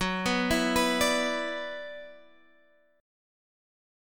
F#7sus4#5 chord